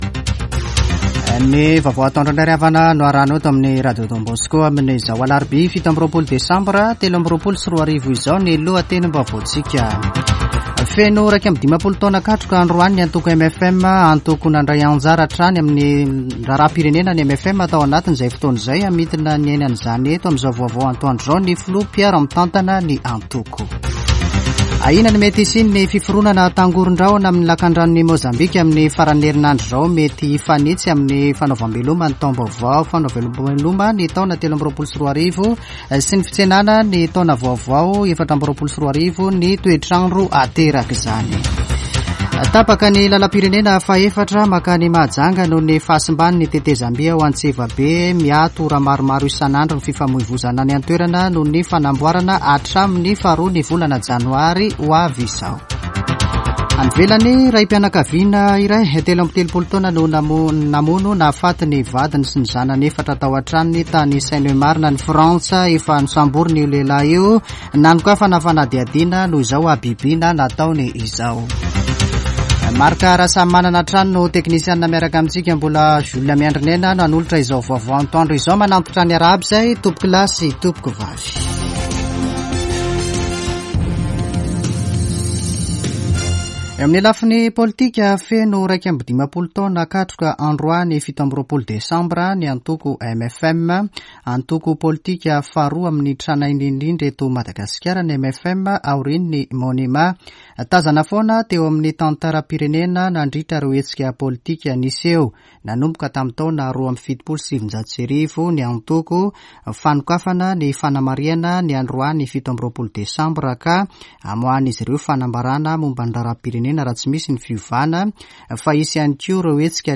[Vaovao antoandro] Alarobia 27 desambra 2023